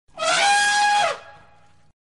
Elephant Call
Elephant Call is a free animals sound effect available for download in MP3 format.
# elephant # trumpet # wild About this sound Elephant Call is a free animals sound effect available for download in MP3 format.
081_elephant_call.mp3